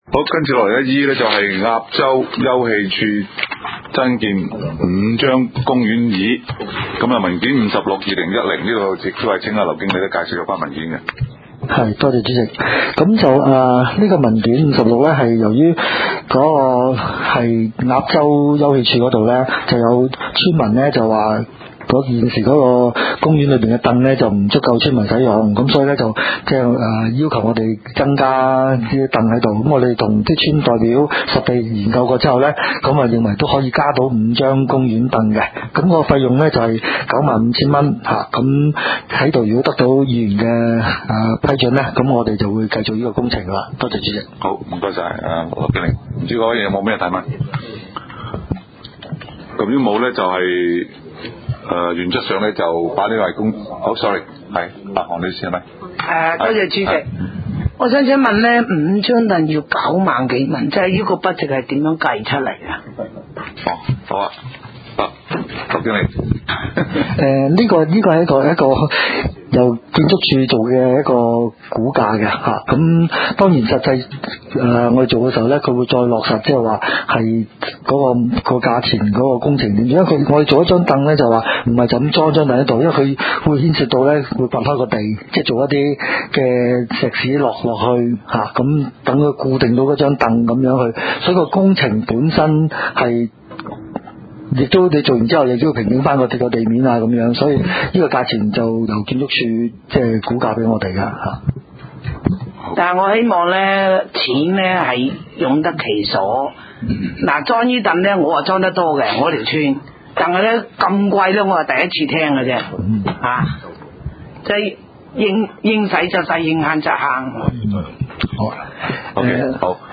地點 北區區議會會議室